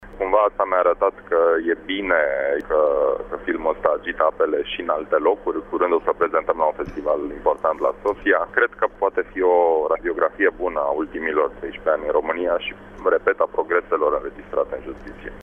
Cei din țările vecine României s-au declarat impresionați de ecranizare a spus regizorul Tudor Giurgiu: